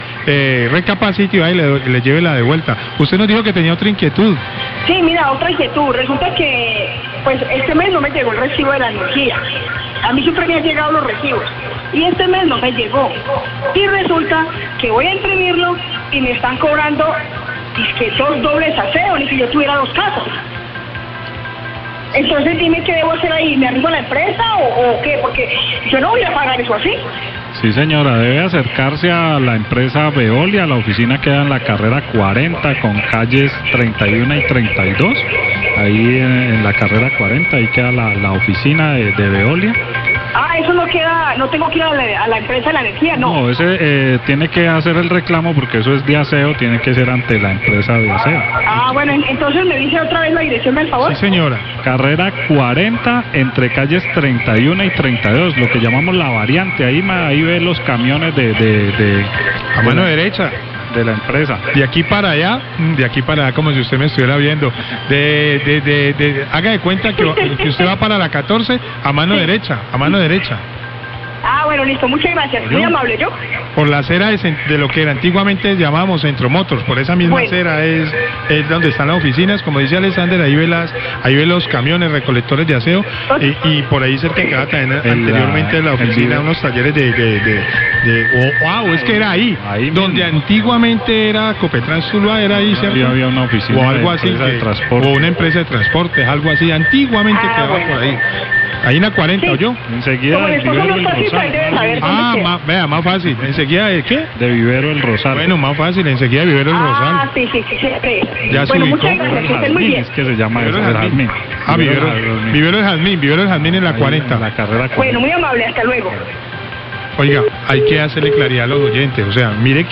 Radio
Los periodistas señalaron que el reclamo debe hacerlo ante la empresa de aseo.